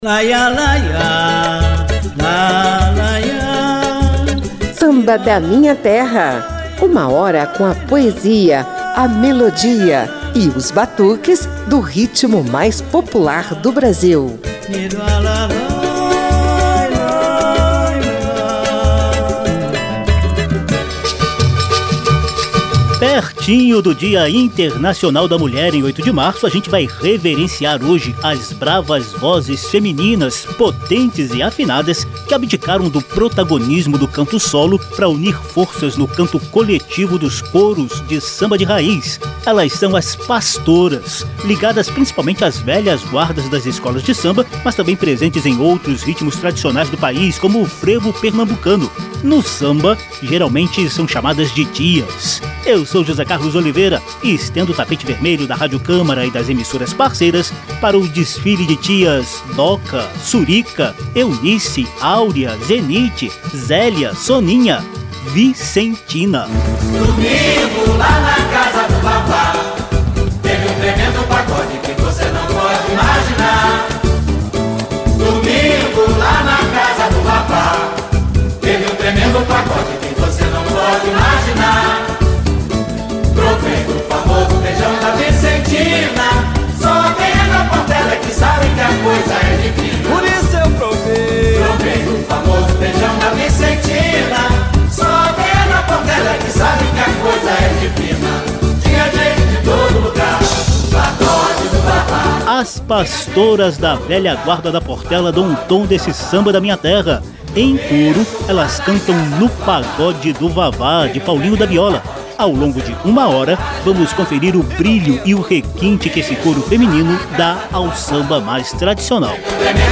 Abrindo o Mês Internacional da Mulher, Samba da Minha Terra reverencia as potentes e afinadas vozes femininas que abdicaram do protagonismo do canto solo para unir forças no canto coletivo dos coros do samba de raiz. Elas são as “pastoras”, muito ligadas às velhas guardas das escolas de samba, mas também presentes em outros ritmos tradicionais, como o frevo pernambucano, a marcha-rancho e a seresta.